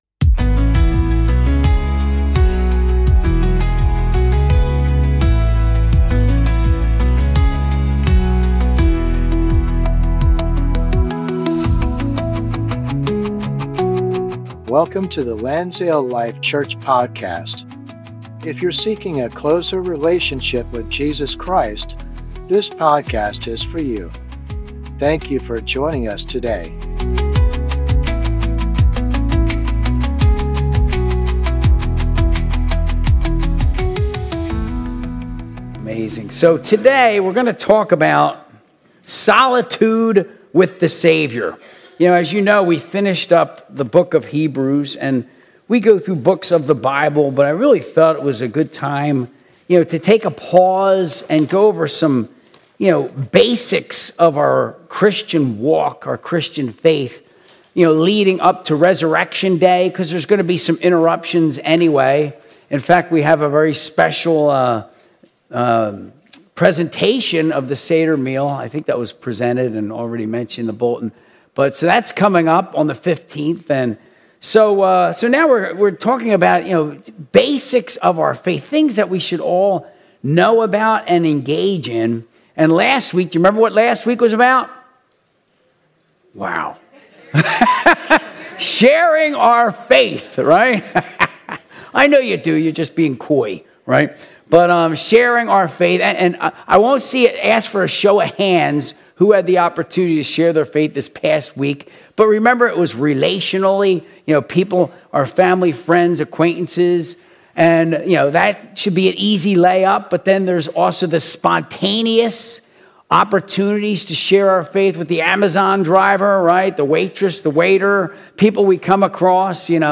Sunday Service - 2026-03-01